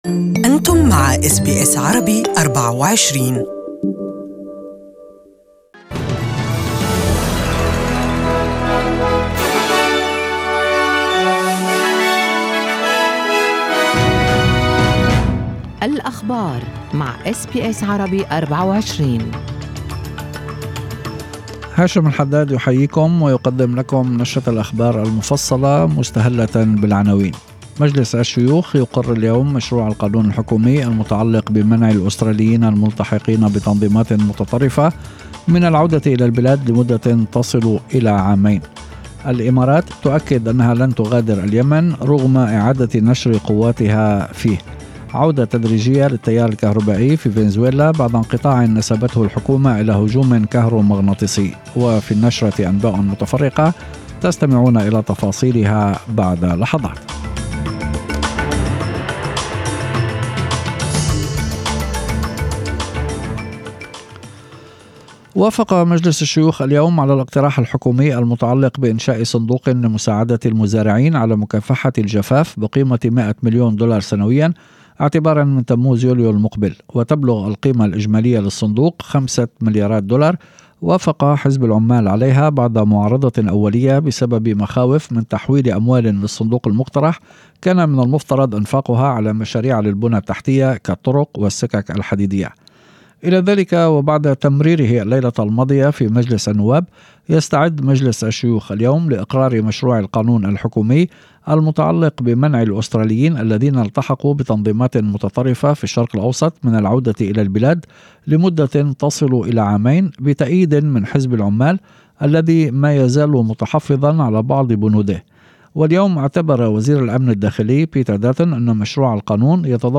Evening News :Dutton adamant foreign fighter laws will contain appropriate safeguards